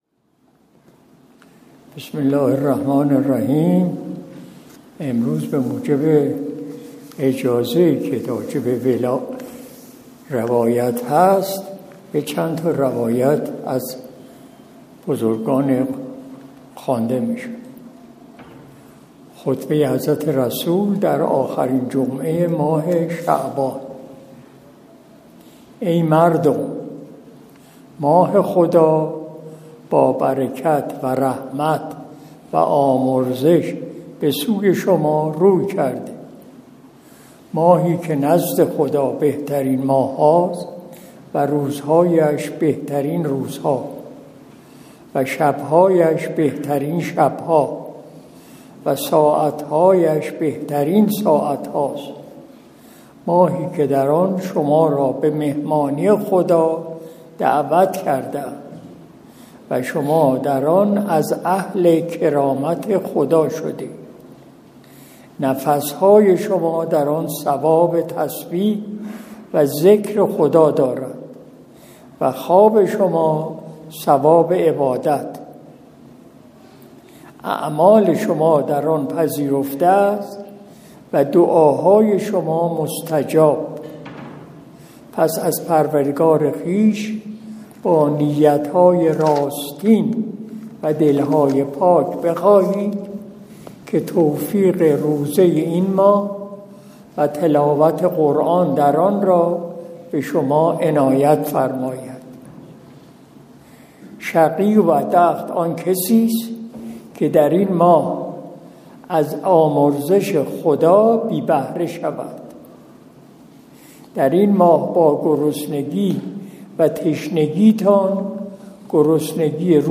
قرائت روایاتی از پیامبر (ص) و ائمه معصومین (عهم) درباره روزه